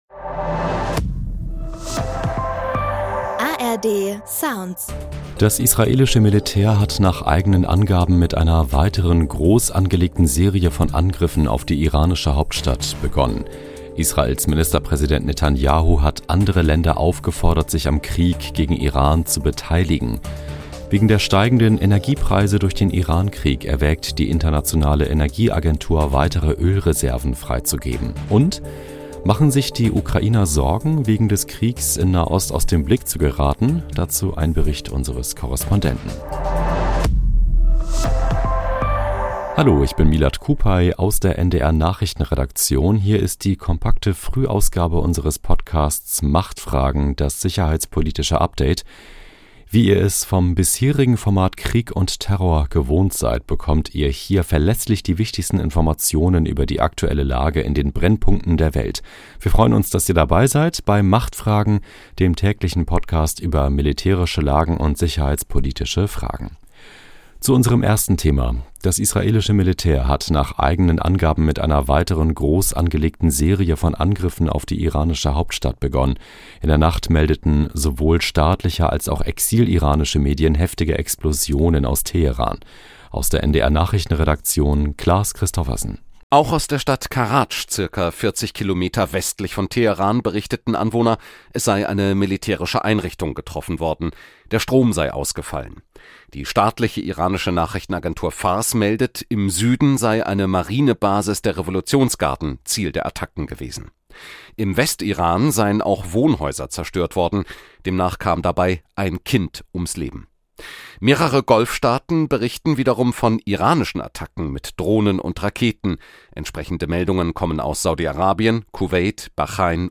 Dazu ein Bericht unseres Korrespondenten.